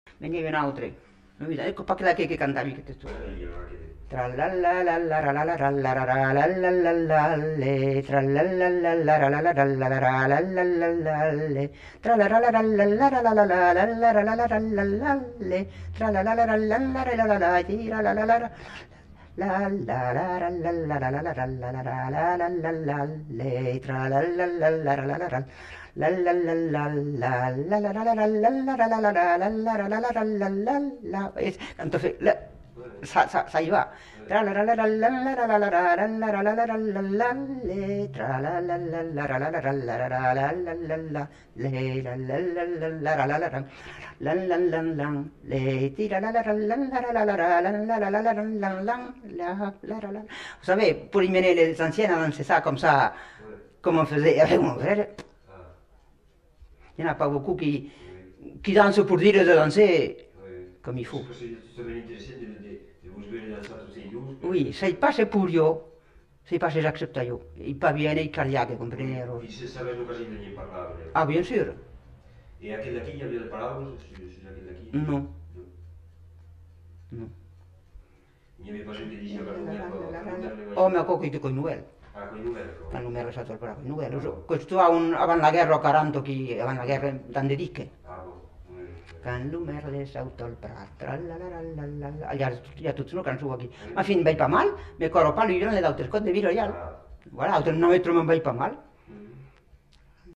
Rondeau (fredonné)
Aire culturelle : Haut-Agenais
Lieu : Castillonnès
Genre : chant
Effectif : 1
Type de voix : voix de femme
Production du son : fredonné
Danse : rondeau